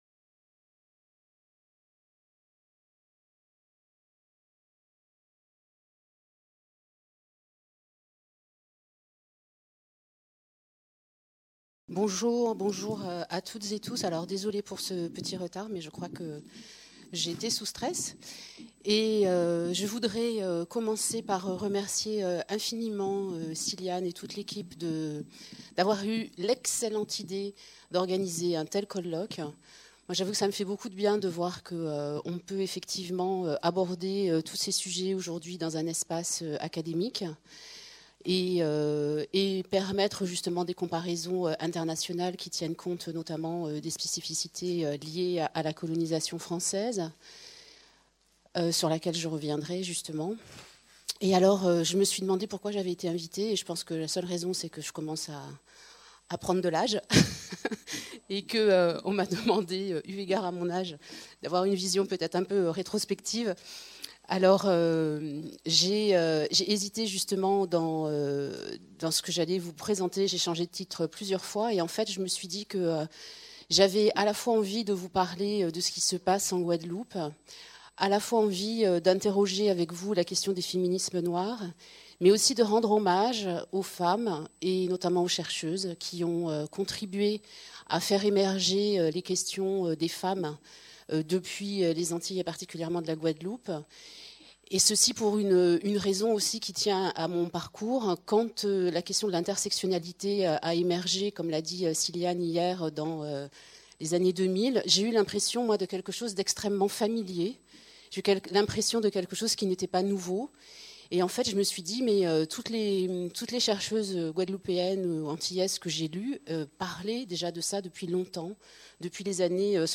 Conférence d'ouverture | Canal U